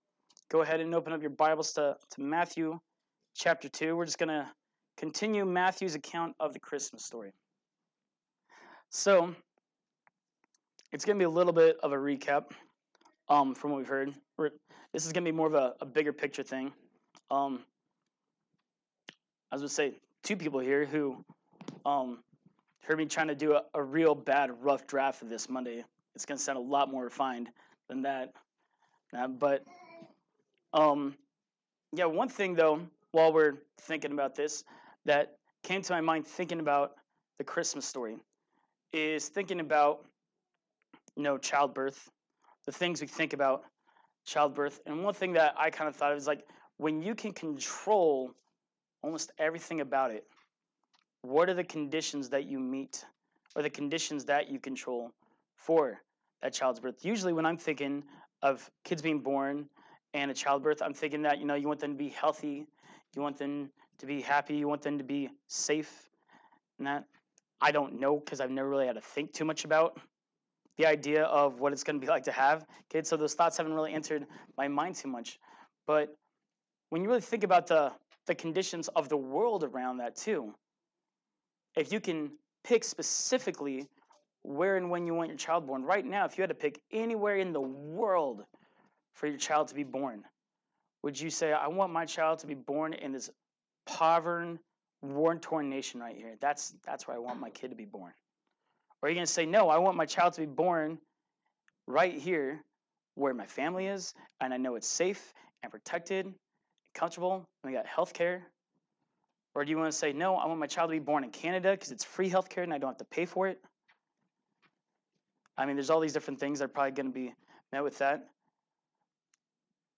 Matthew 2:13-23 Service Type: Sunday Morning Worship « Matthew 2:1-12